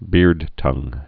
(bîrdtŭng)